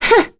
Lewsia_B-Vox_Attack2.wav